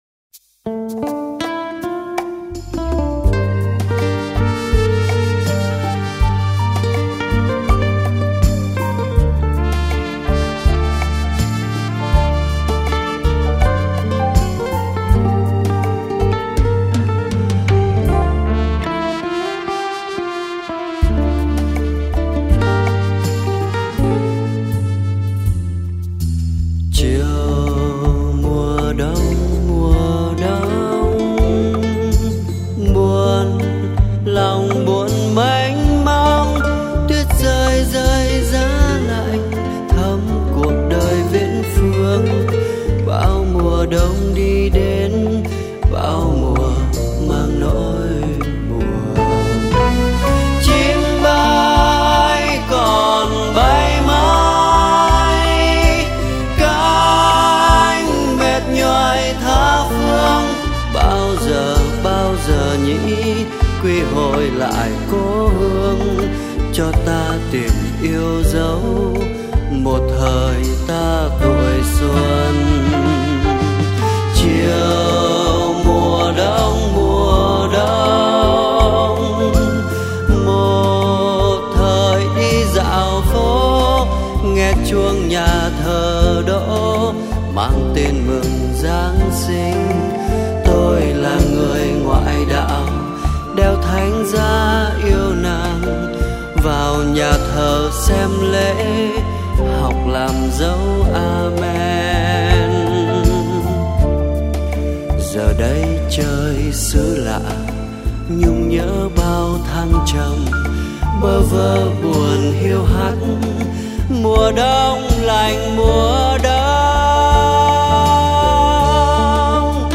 Tiếng hát